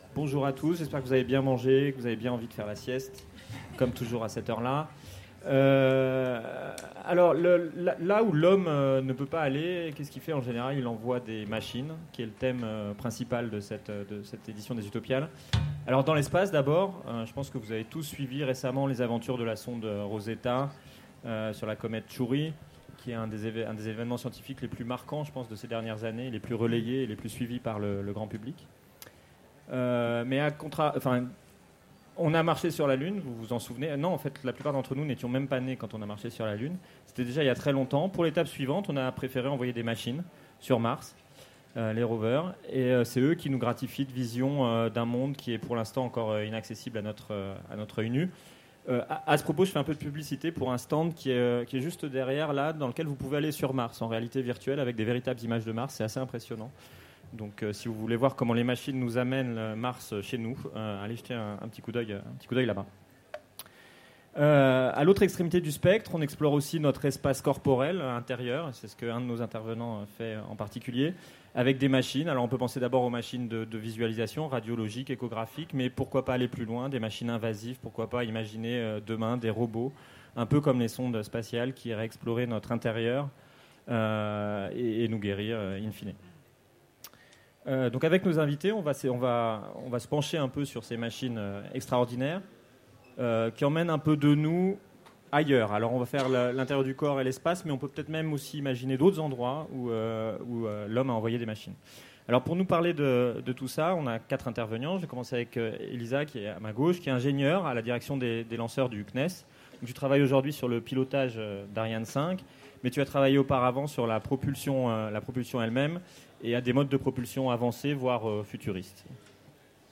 Utopiales 2016 : Conférence La machine est un explorateur solitaire